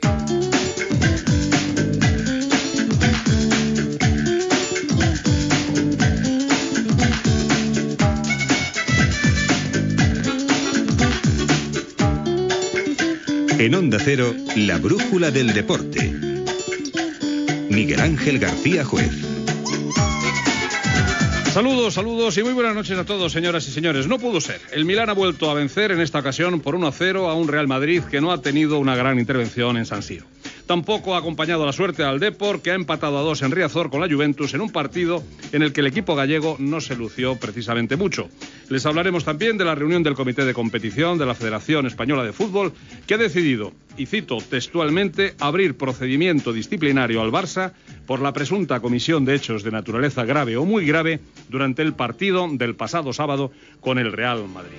Careta i inici del programa amb resultats de futbol internacional i una notícia sobre el Futbol Club Barcelona
Esportiu